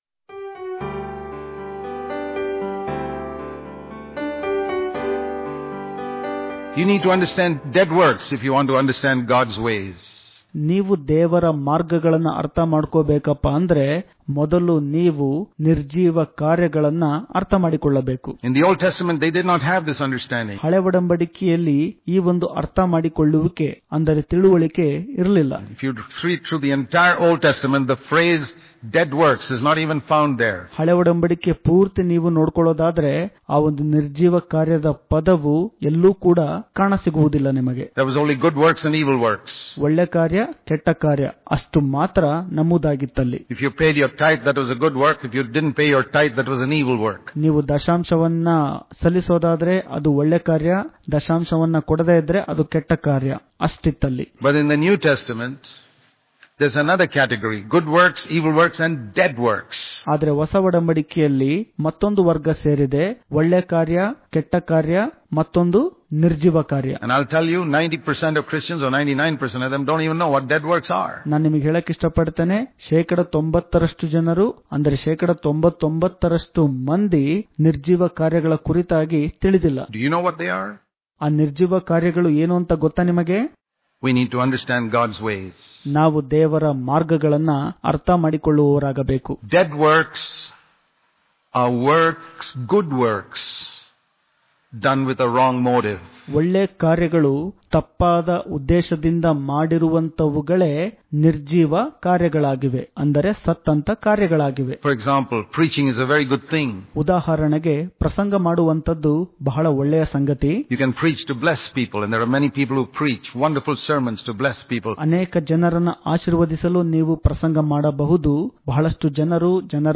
September 12 | Kannada Daily Devotion | Understanding Dead Works - Part 1 Daily Devotions